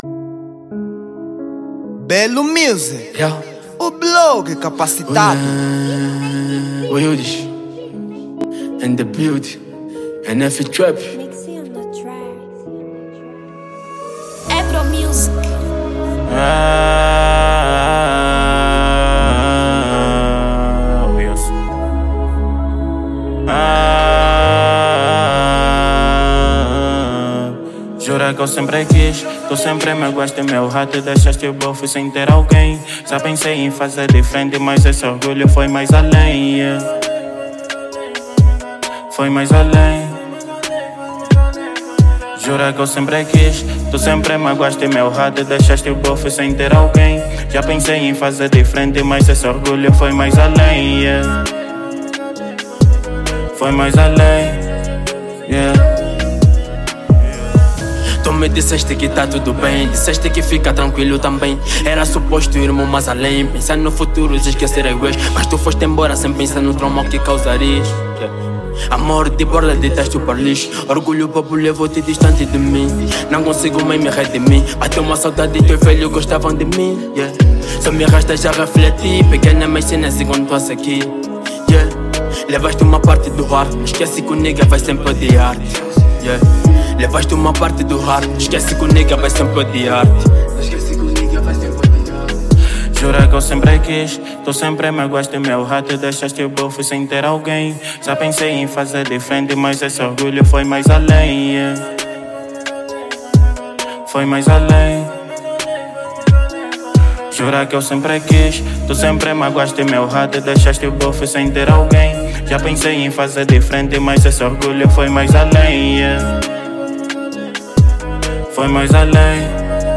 Género: Rap